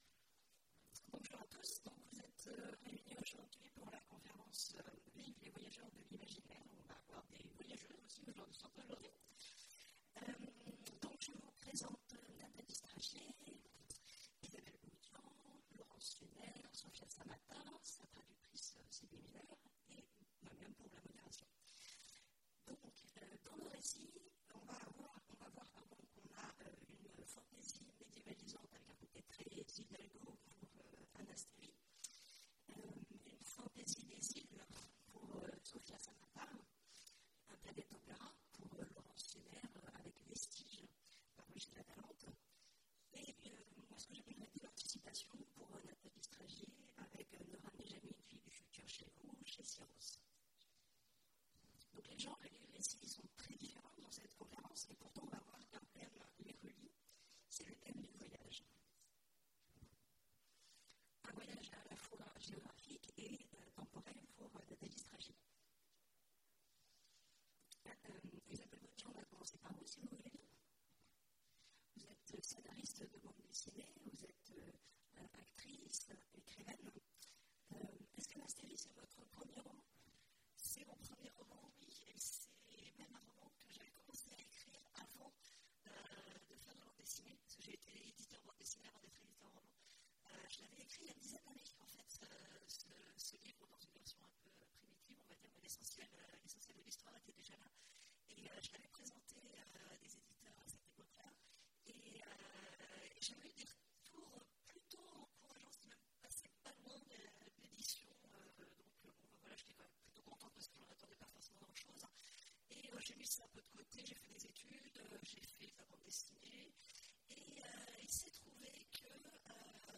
Mots-clés Voyages Conférence Partager cet article